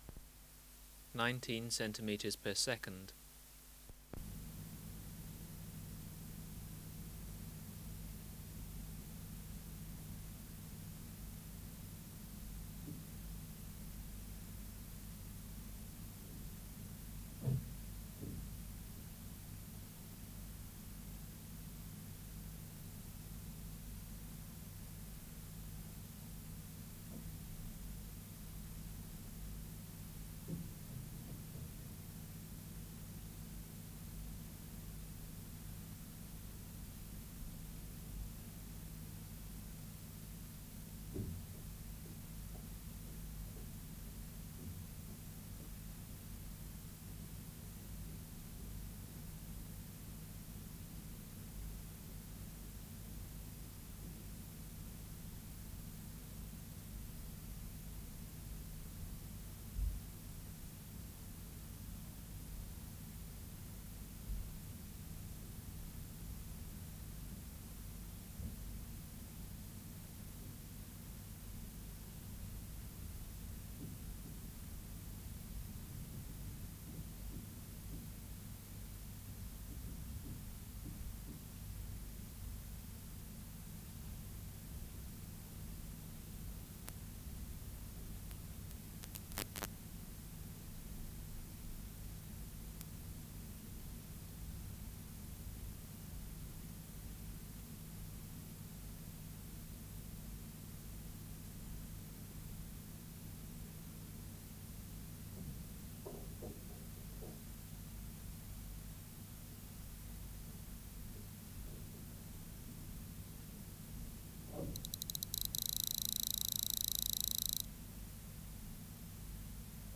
Recording Location: BMNH Acoustic Laboratory
Reference Signal: 1 kHz for 10 s
Extraneous Noise: Faint typing Substrate/Cage: Large glass jar
Microphone & Power Supply: Sennheiser MKH 405 Distance from Subject (cm): 30
Recorder: Kudelski Nagra IV D (-17dB at 50 Hz)